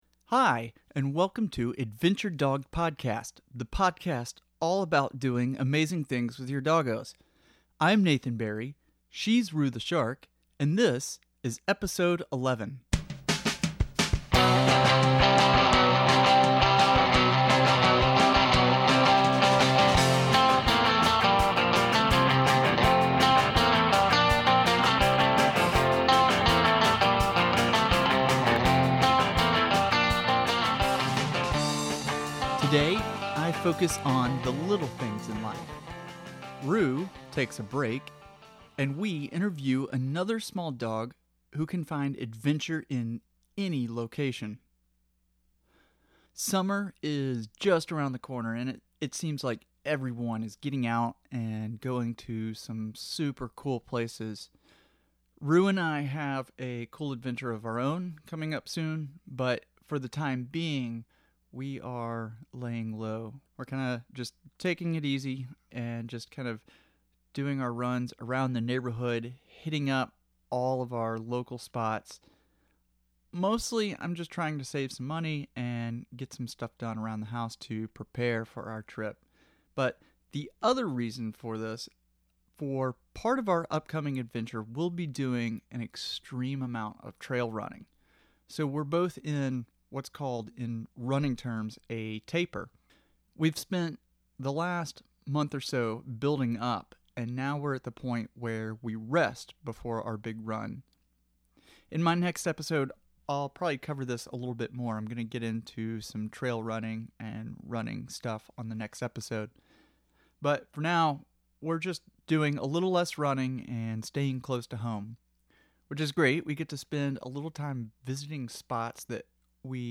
Today’s interview